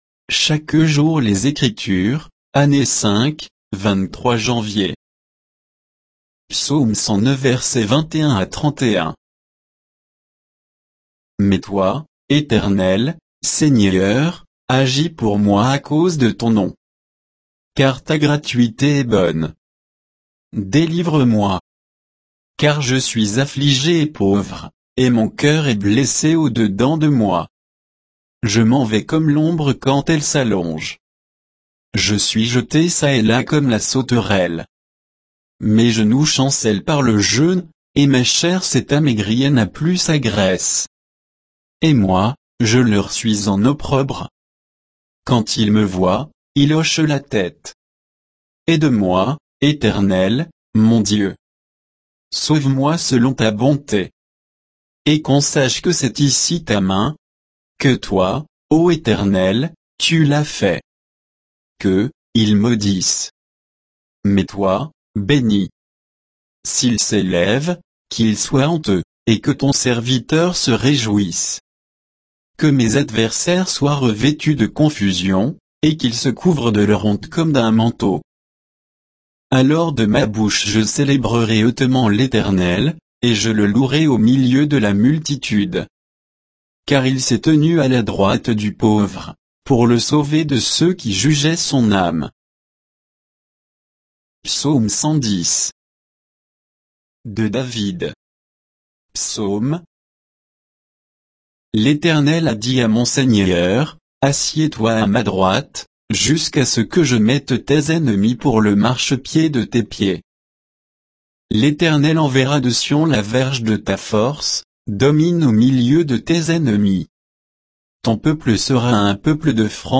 Méditation quoditienne de Chaque jour les Écritures sur Psaumes 109